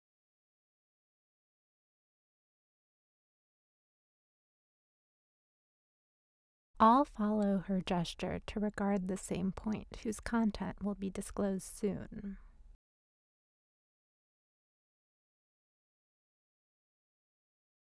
clicking on my narration from the engineer's booth.